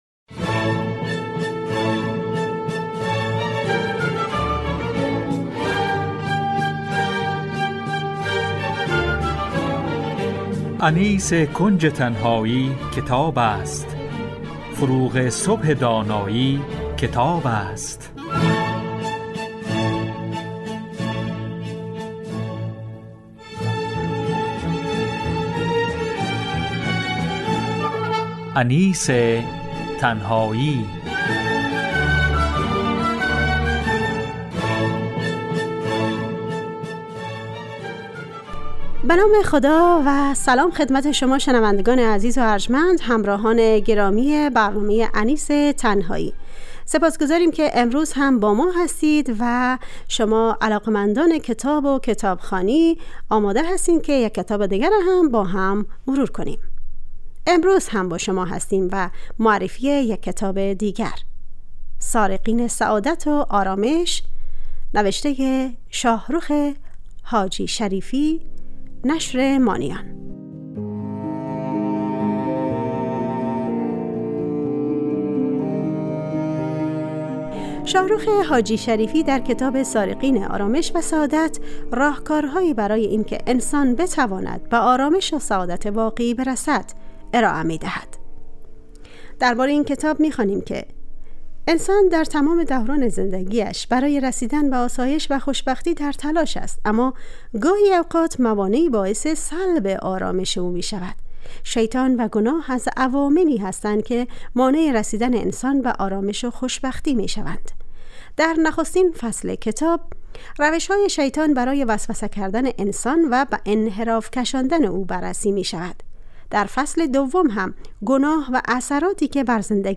معرفی کتاب